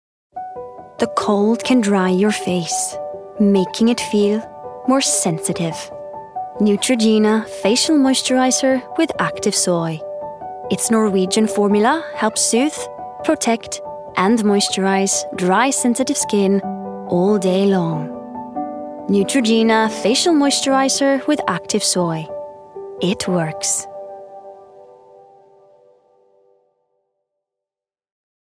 Scandinavian Accent Showreel
Female
Bright